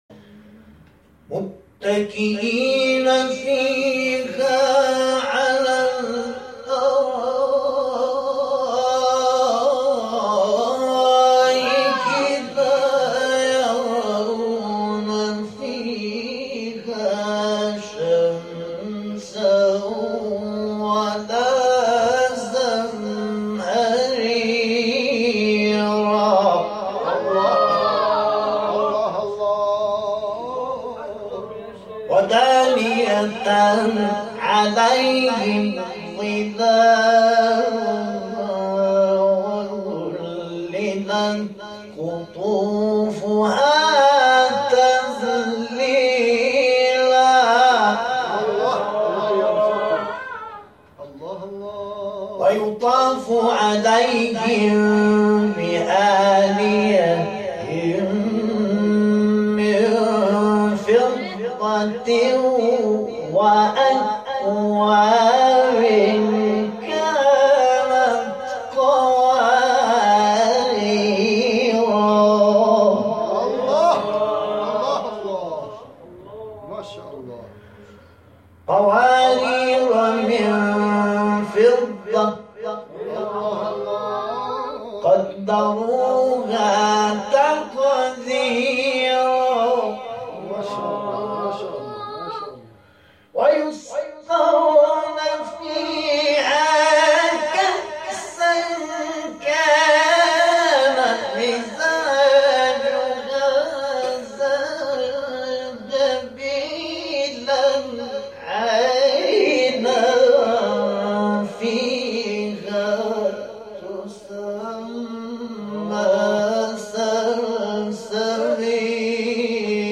گروه شبکه اجتماعی: نغمات صوتی از تلاوت قاریان بین‌المللی و ممتاز کشور که به تازگی در شبکه‌های اجتماعی منتشر شده است، می‌شنوید.
سوره مبارکه انسان در مقام سه گاه